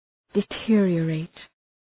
Προφορά
{dı’tıərıə,reıt}